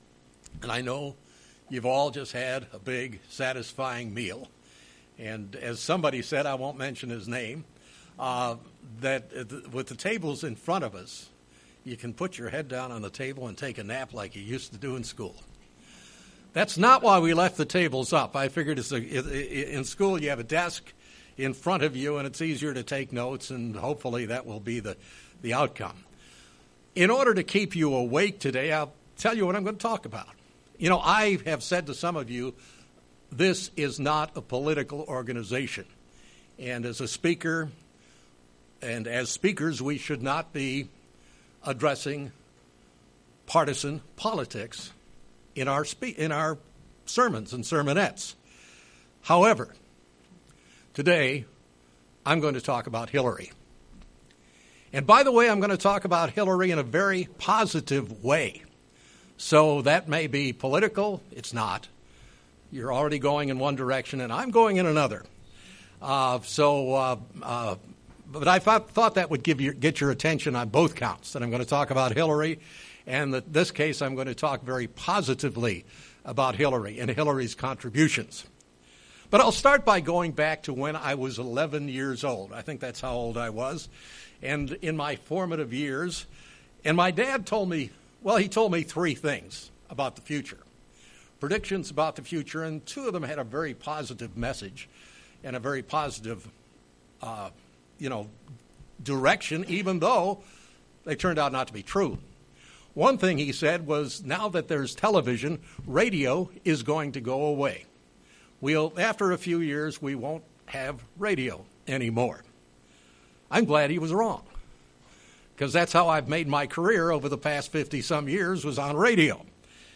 Given in Springfield, MO
UCG Sermon Studying the bible?